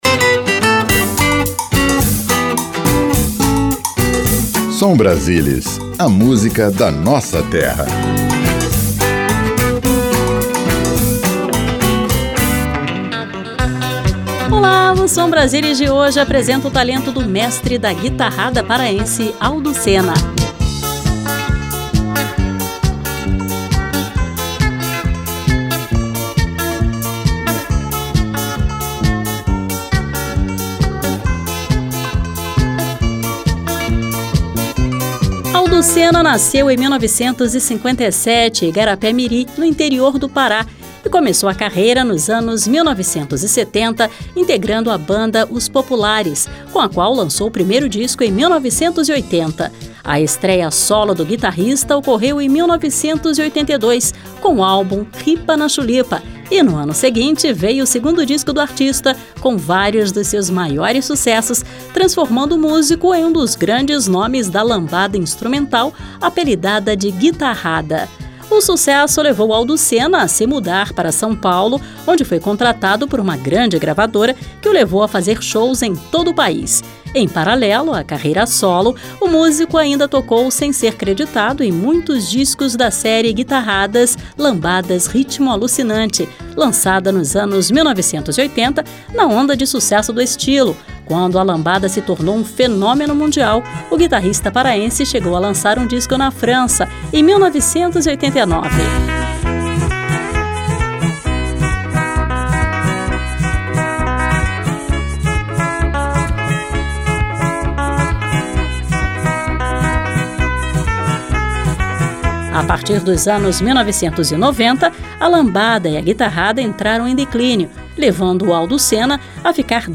guitarrista